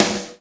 SNR HARD 02L.wav